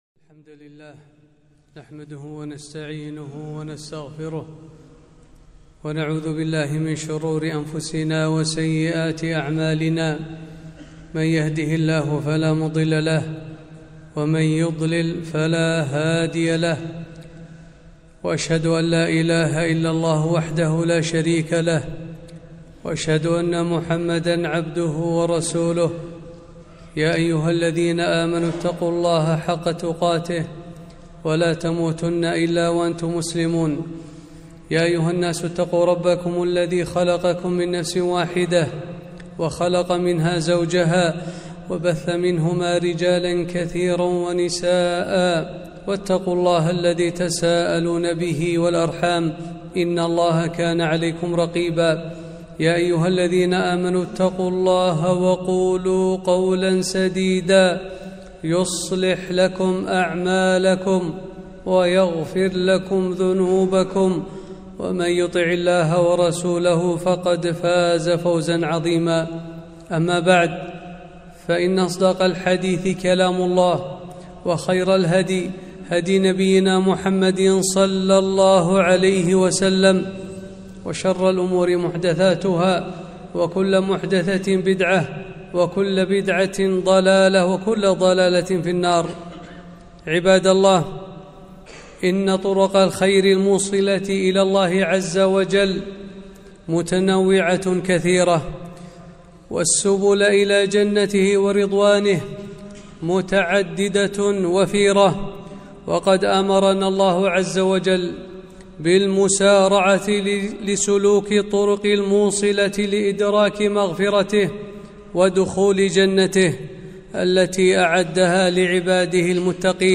خطبة - فضل الصدقة